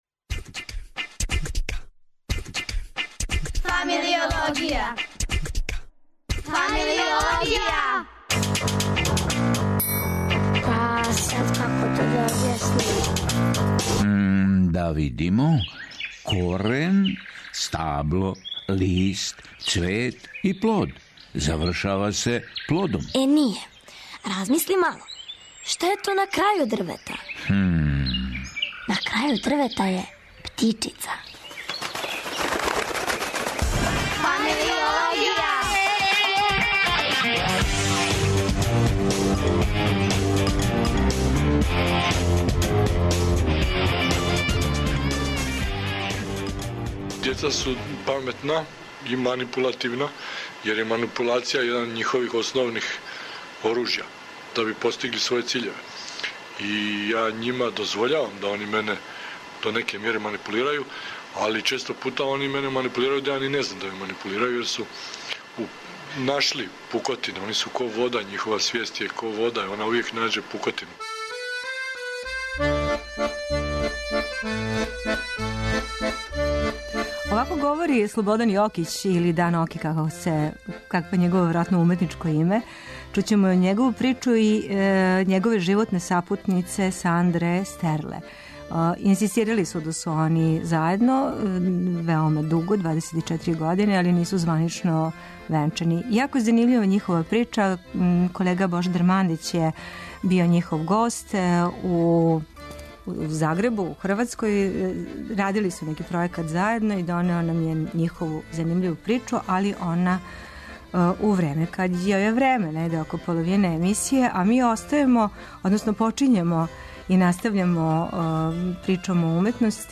А, своју Породичну причу казују животни партнер: